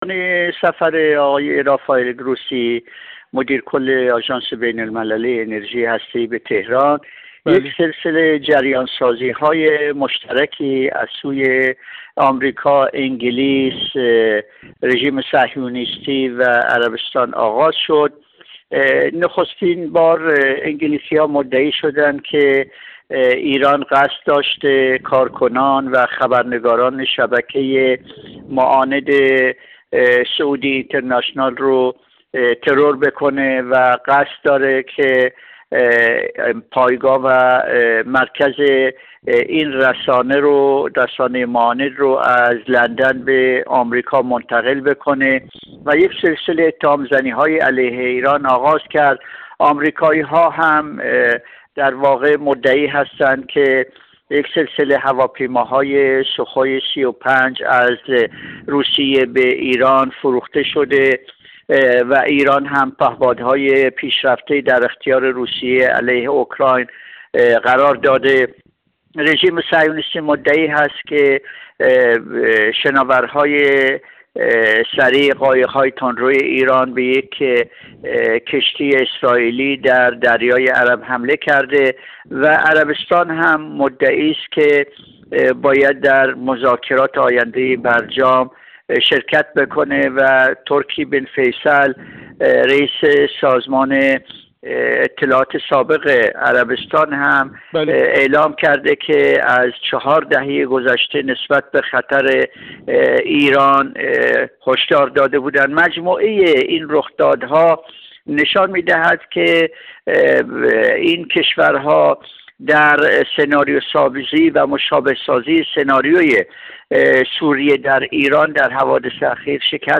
کارشناس مسائل منطقه
گفت‌وگو